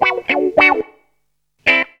GTR 27 AM.wav